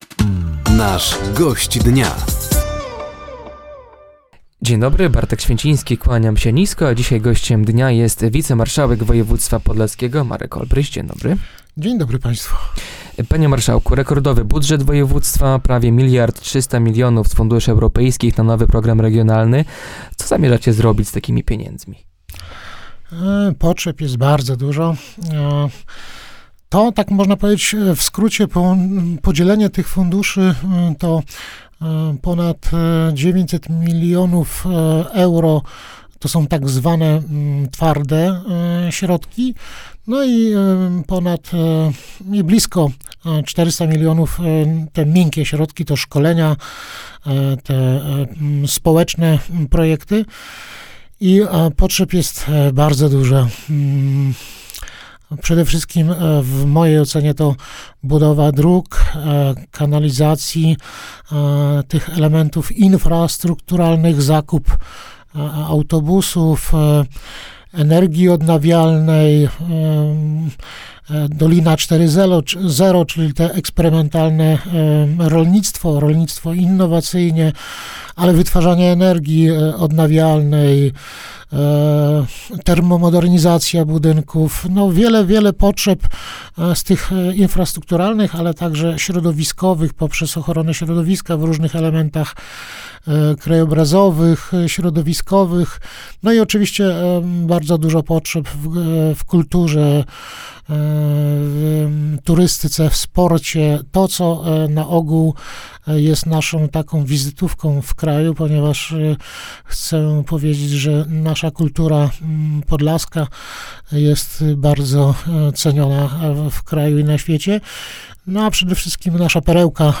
Gościem Dnia Radia Nadzieja był wicemarszałek województwa podlaskiego, Marek Olbryś. Tematem rozmowy był między innymi budżet samorządu na ten rok, powrót kolei do Łomży i największe problemy regionu.